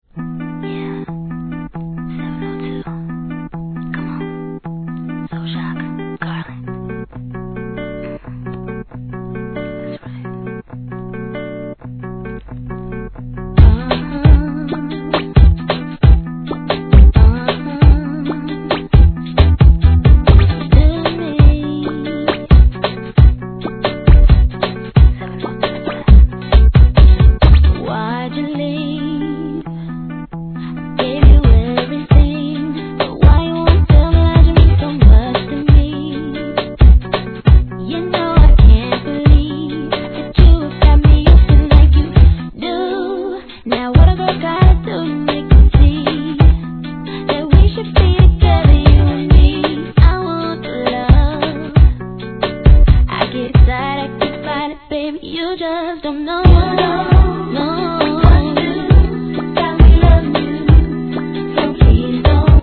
HIP HOP/R&B
変則BEATにキュートなコーラスがはまる女性のLOVEソング